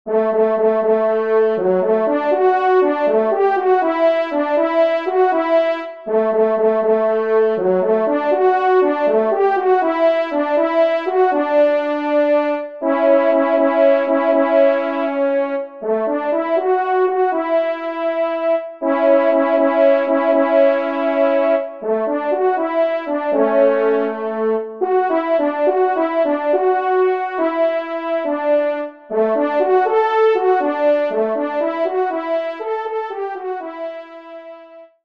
Genre :  Divertissement pour Trompes ou Cors en Ré
1eTrompe